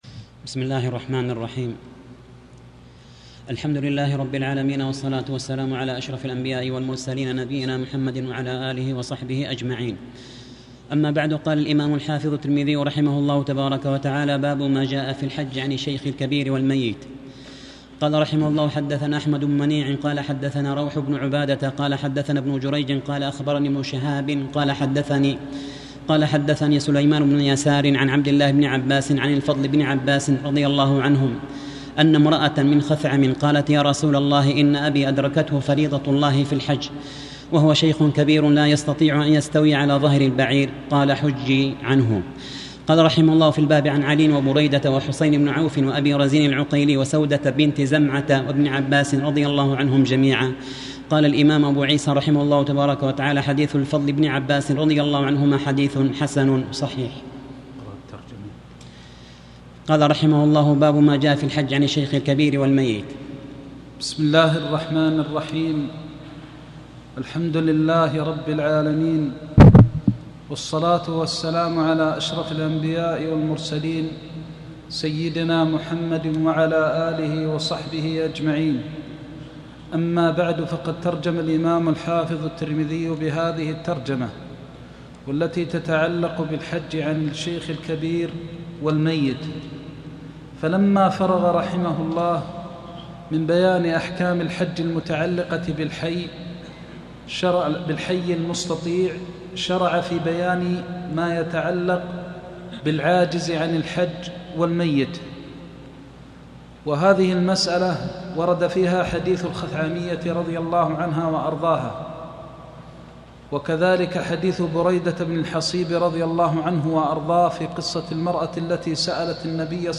Ansarallah درس سنن الترمذي396